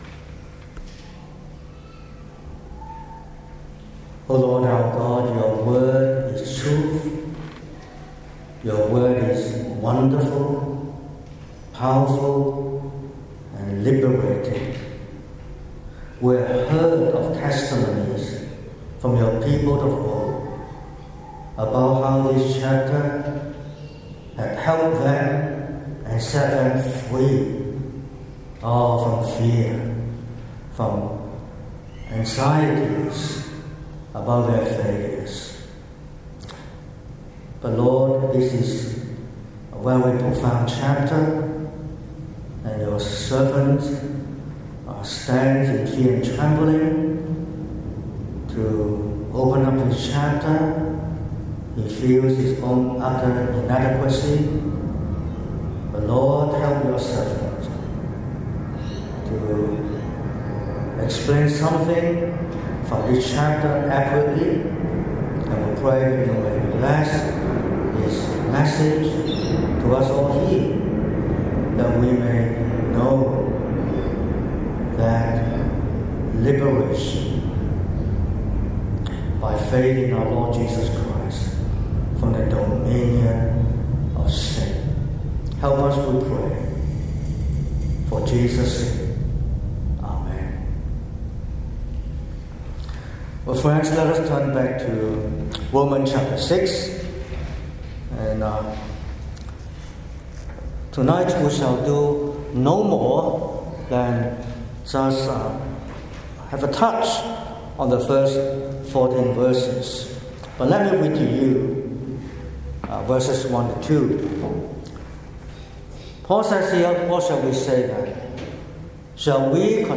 07/09/2025 – Evening Service: Adam and Christ